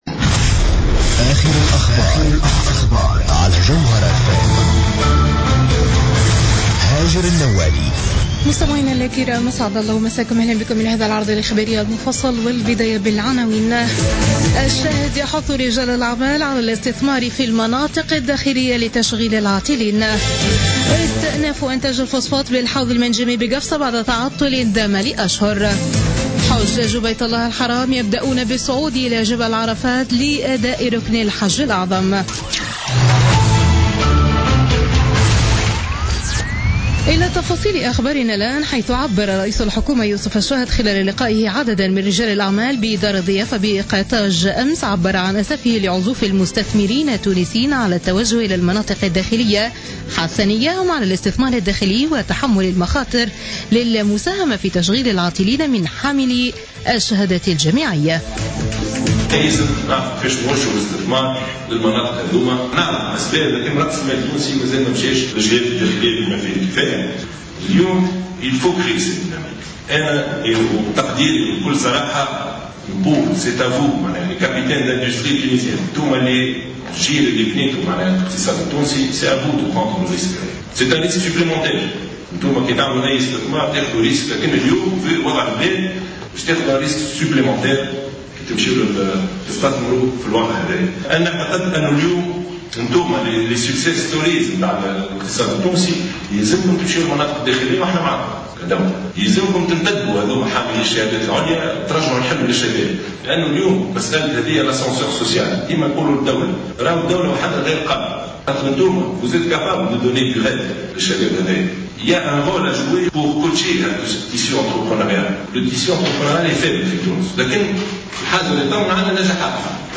Journal Info 00h00 du dimanche 11 Septembre 2016